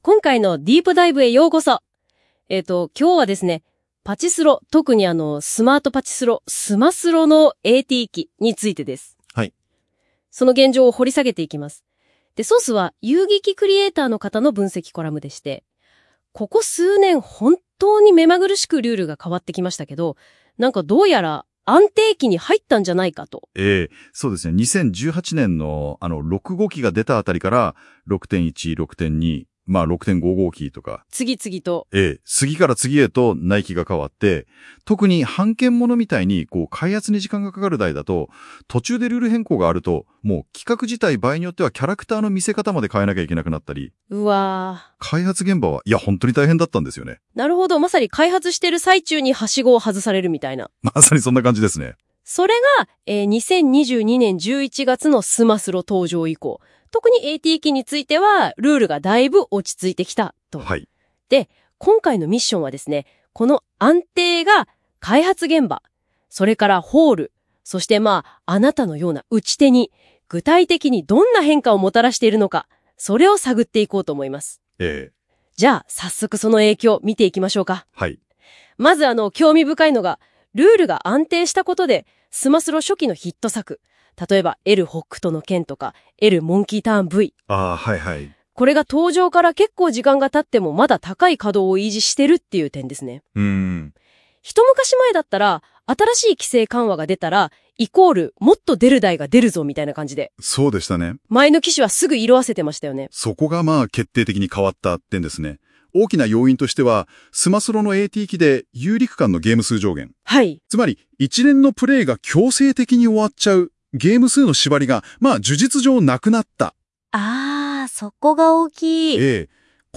※AIで生成／試験運用中